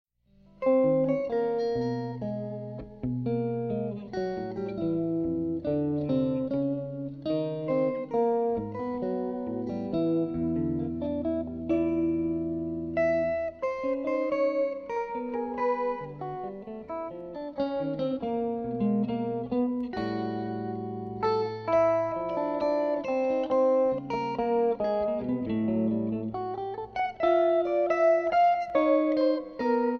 Guitar
Two Electric Guitar Entwine
Lyrical Jazz, Blues and Rock inflected Duets